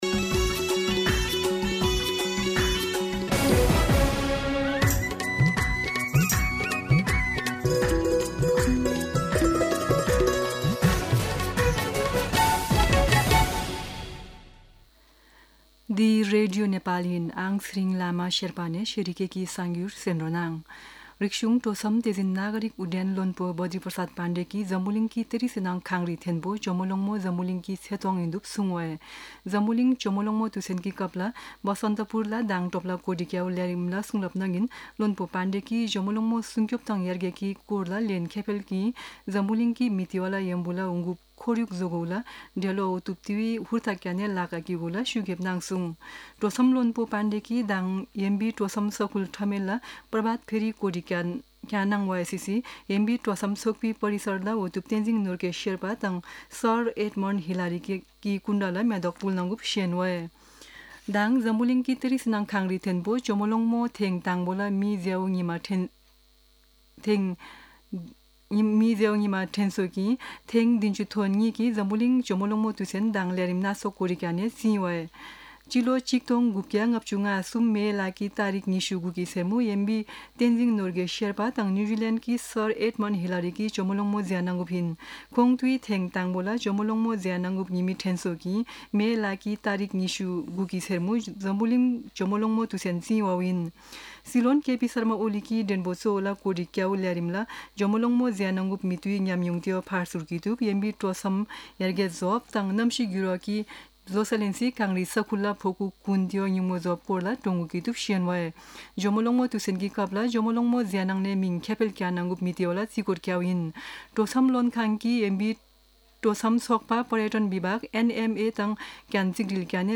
शेर्पा भाषाको समाचार : १६ जेठ , २०८२
Sherpa-News-02-16.mp3